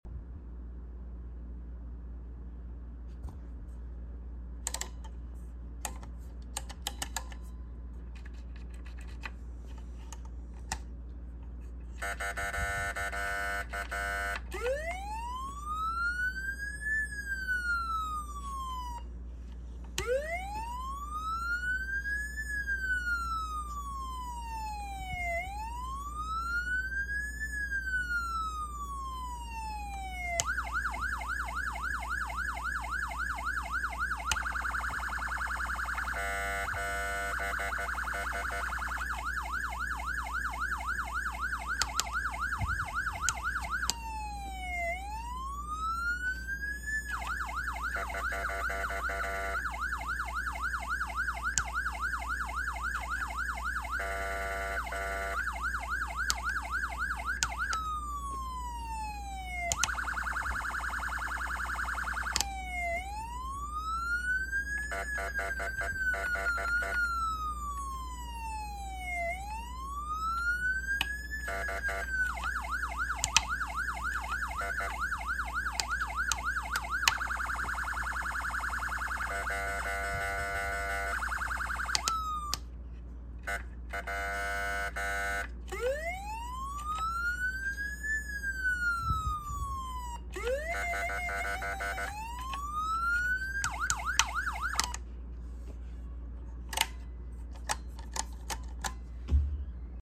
Code 3 Streicher’s VC7 Strike Force siren, aka code 3 vcon